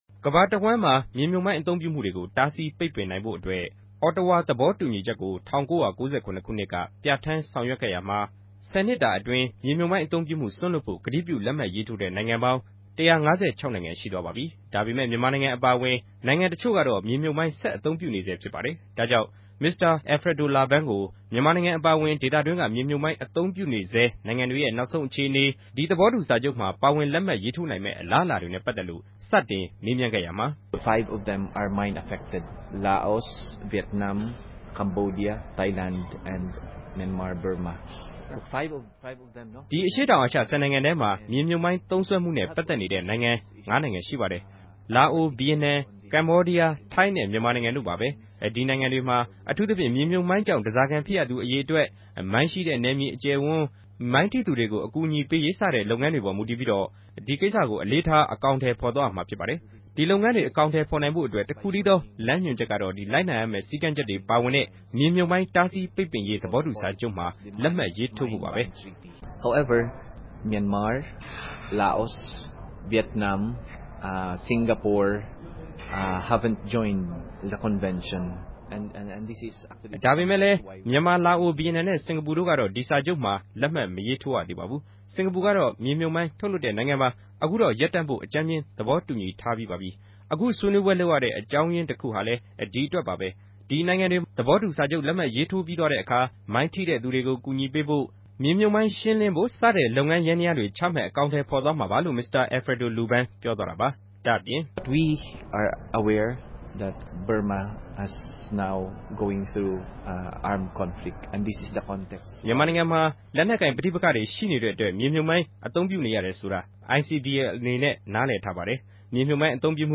တြေႚဆုံမေးူမန်းခဵက်။